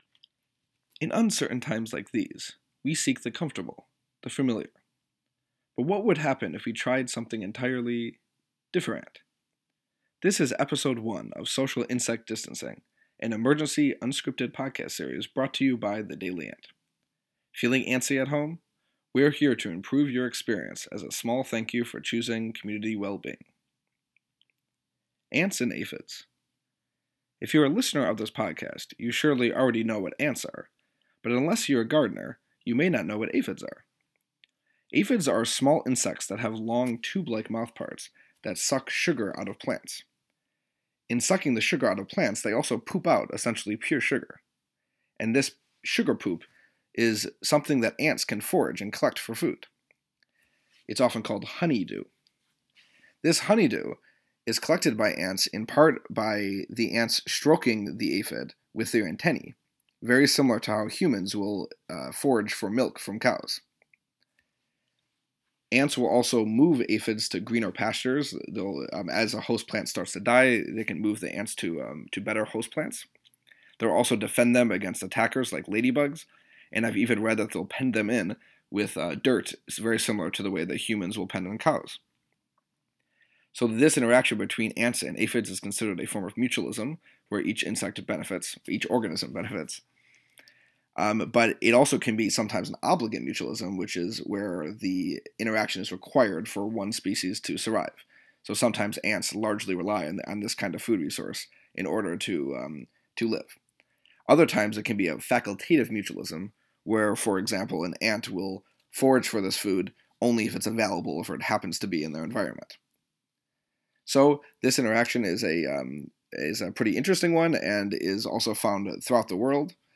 In response to the ongoing pandemic, we will be producing a short, mostly unscripted, no-frills daily podcast series: “Social Insect Distancing”. We hope that learning a little about ants will provide cross-species comfort for anxious human readers!